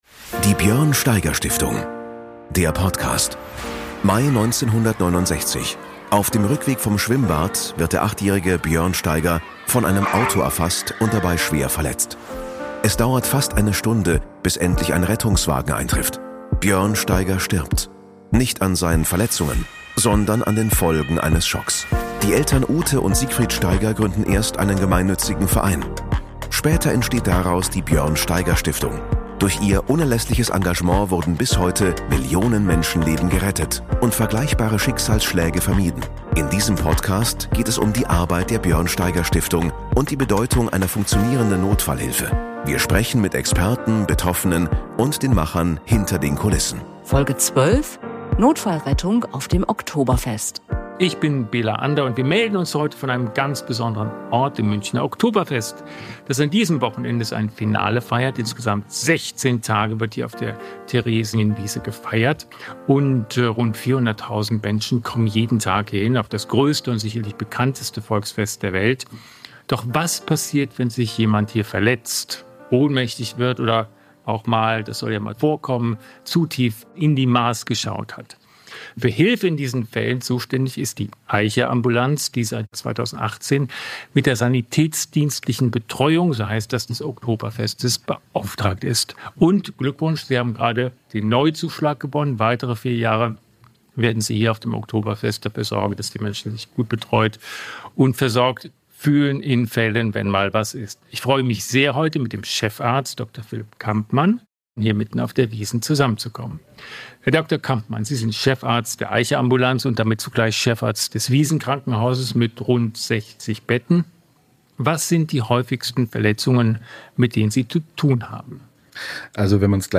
erfahrt ihr im Gespräch